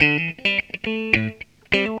GTR 1  AM.wav